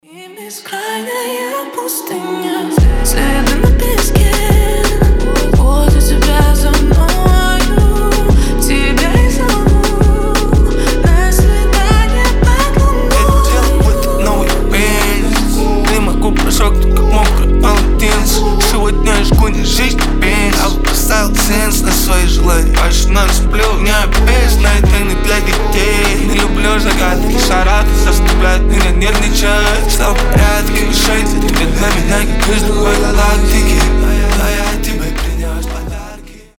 trap
атмосферные
дуэт